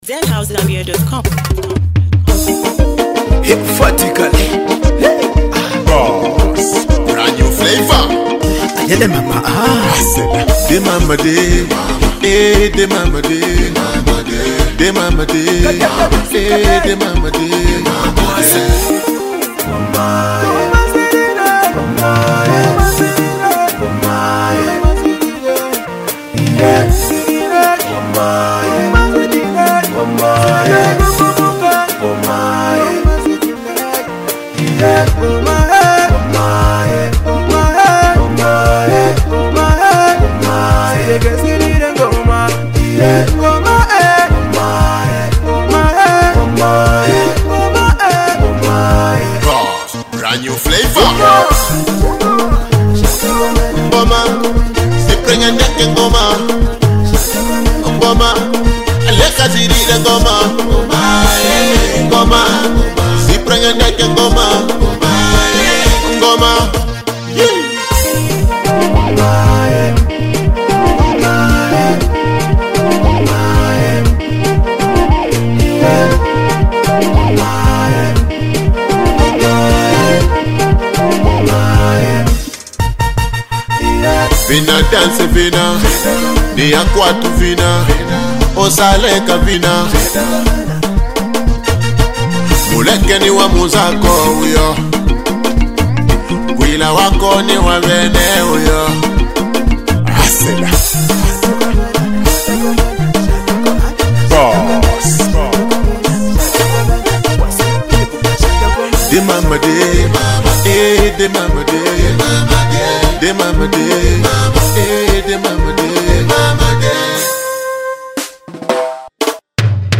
Categories: GospelMusic